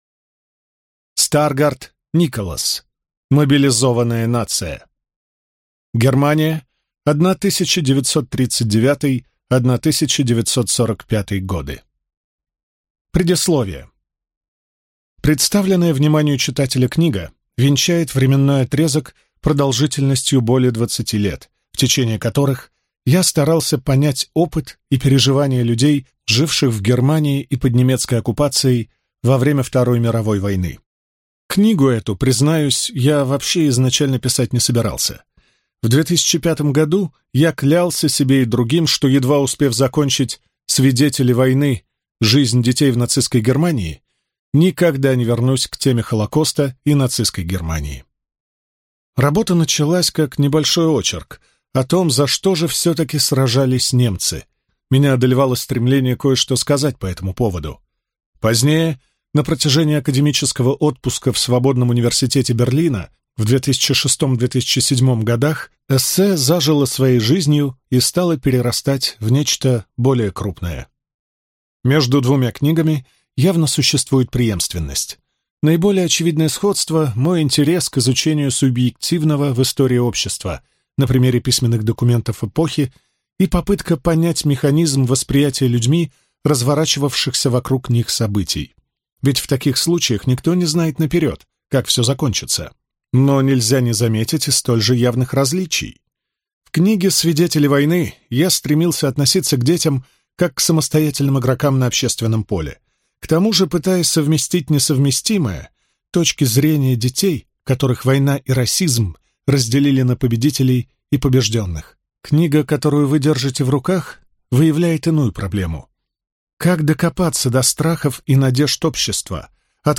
Аудиокнига Мобилизованная нация: Германия 1939–1945 | Библиотека аудиокниг